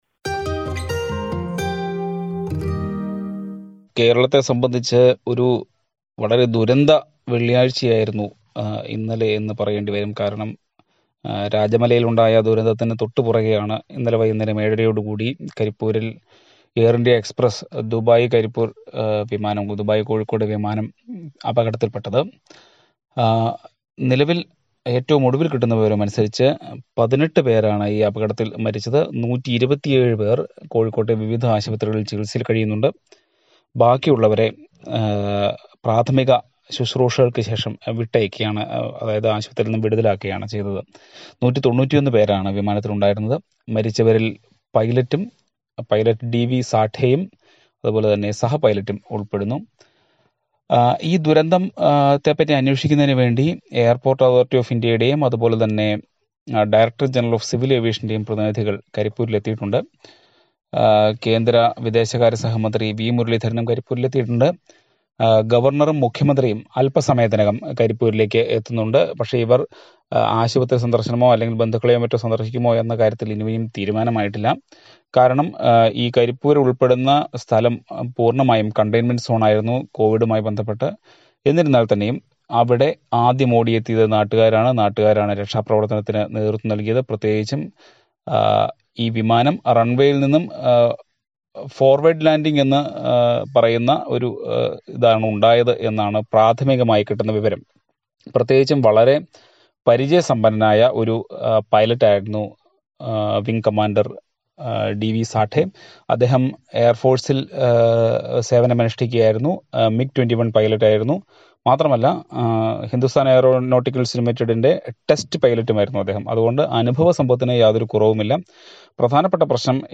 Several lives were lost in multiple tragedies in Kerala yesterday. Listen to a report.